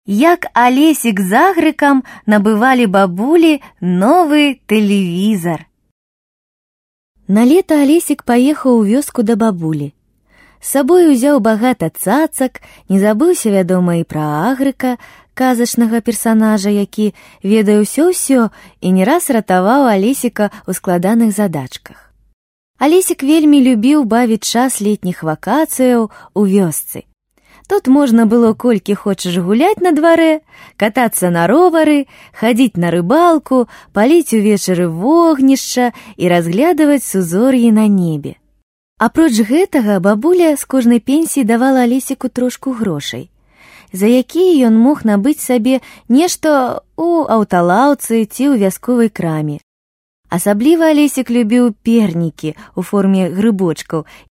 Belarusca Seslendirme
Kadın Ses